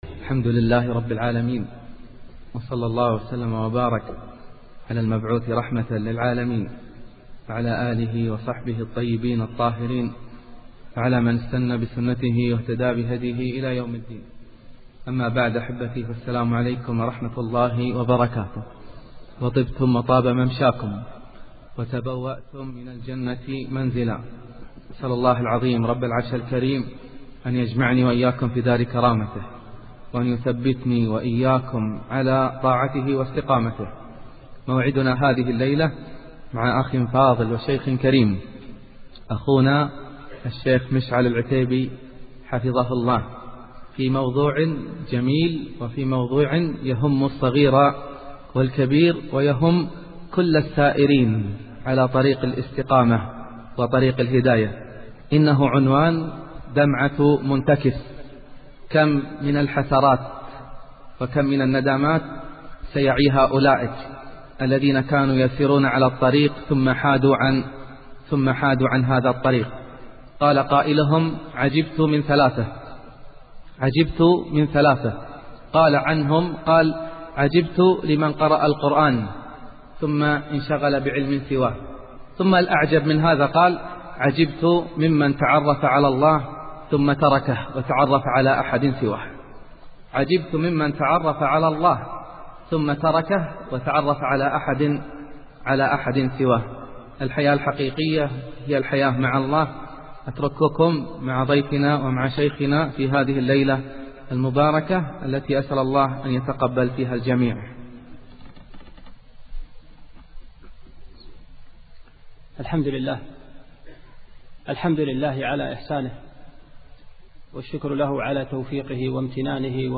(مقدمة واستقبال من المذيع). الافتتاح: تحميد، صلاة على النبي، ترحيب بالحضور، دعاء بالثبات والهداية.